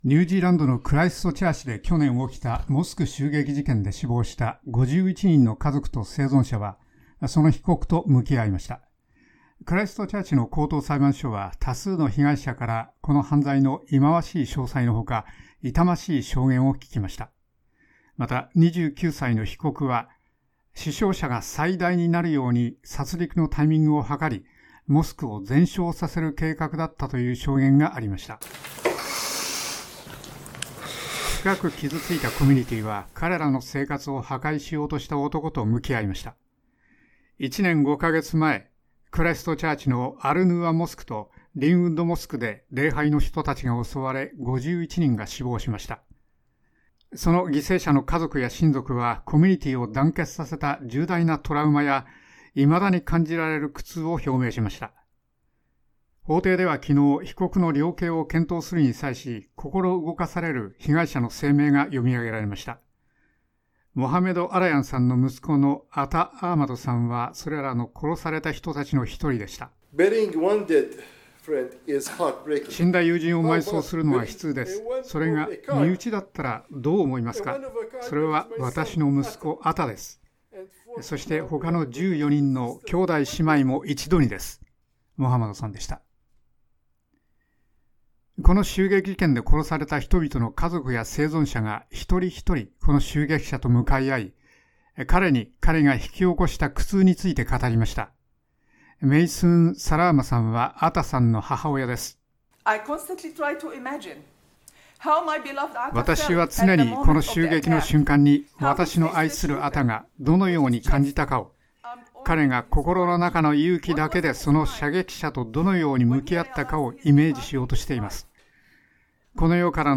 （被告の名前はレポート中１度しか明らかにできないなど、このレポートは法的な規制に従って作られています）